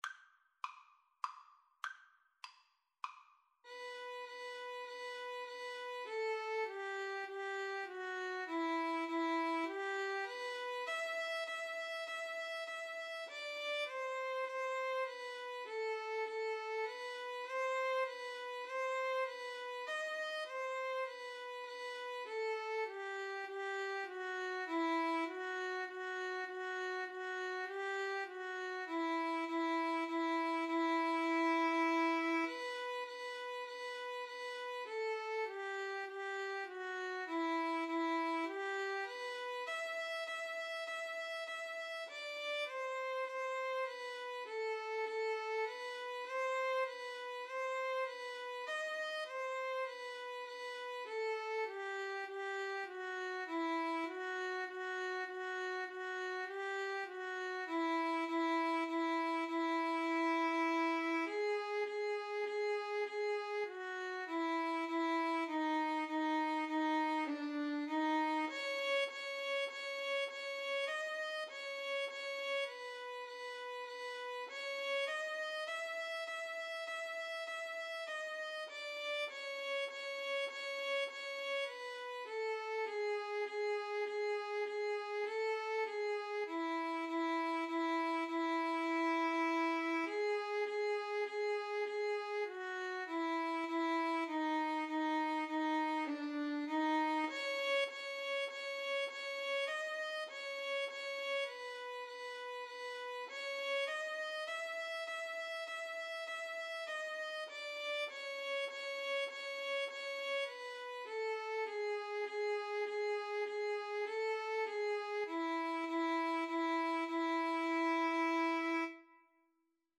Free Sheet music for Violin-Guitar Duet
3/4 (View more 3/4 Music)
G major (Sounding Pitch) (View more G major Music for Violin-Guitar Duet )
Traditional (View more Traditional Violin-Guitar Duet Music)